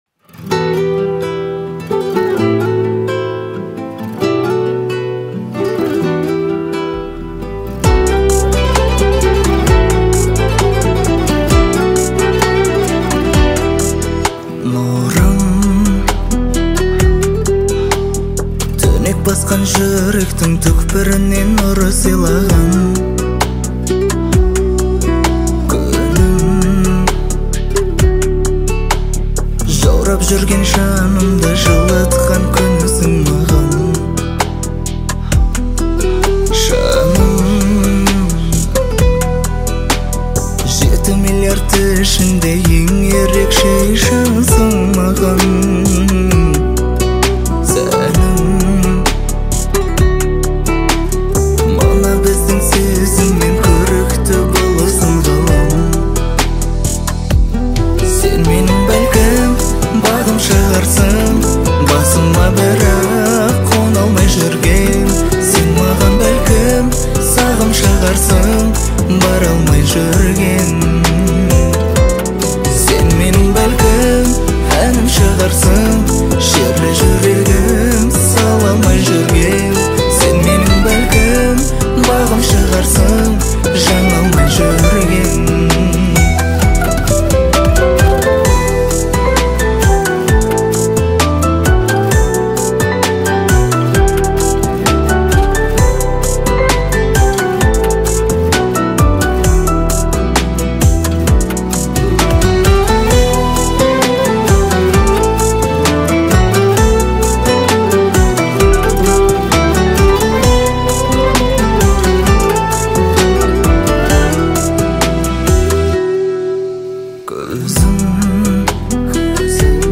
Категория: Казахские,